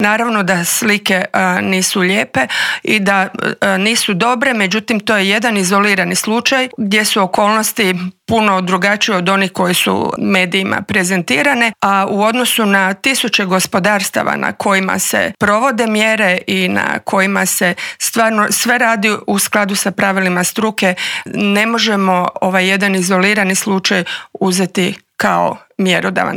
Socio-ekonomske posljedice su ogromne, a o tome kako izaći na kraj s ovom bolesti koja ne pogađa ljude u medicinskom, ali definitivno da u ekonomskom smislu, razgovarali smo u Intervjuu tjedna Media servisa s ravnateljicom Uprave za veterinarstvo i sigurnost hrane Tatjanom Karačić.